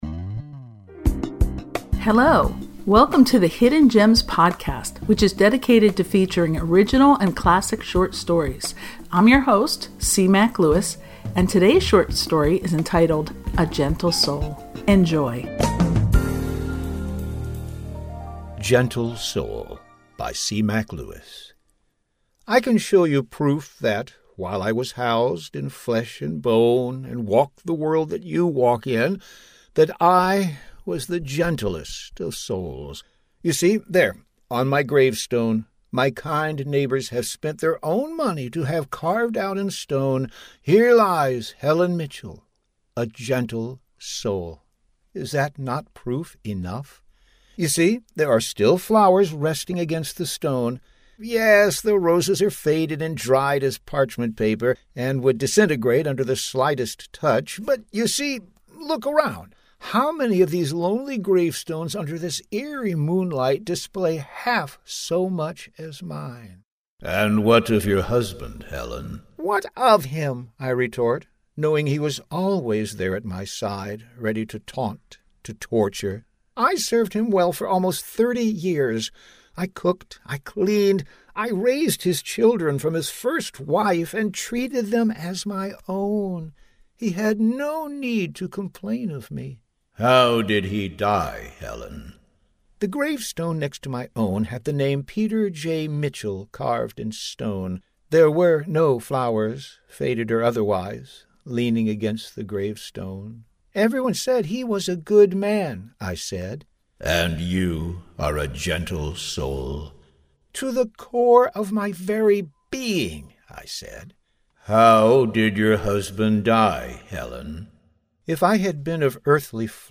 On your gravestone, what words will your loved ones use to describe you? Will you be able to defend those words to your eternal companion, the ever-sarcastic Death? Thank you for listening to The Hidden Gems Podcast, which is dedicated to bringing you the best short stories you've never heard.